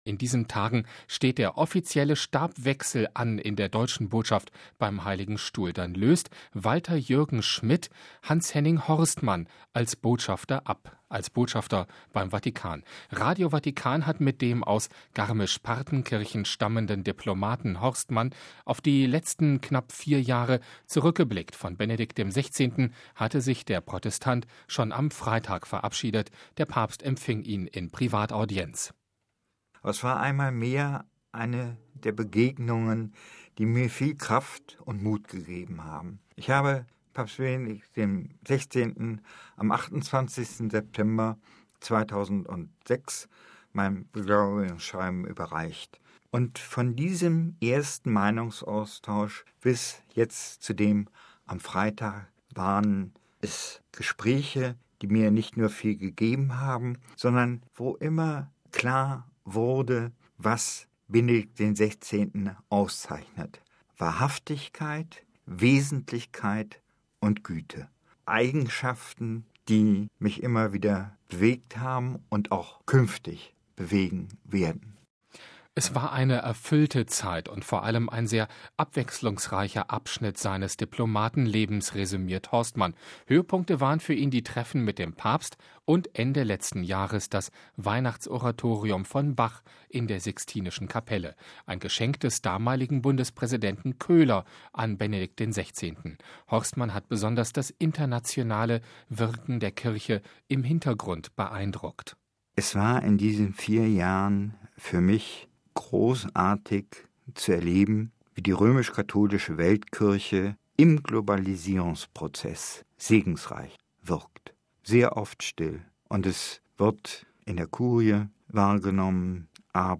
Vatikan: Abschiedsinterview mit Botschafter Horstmann
Seine Worte können trotz sorgsamer Wahl die Rührung nicht verbergen.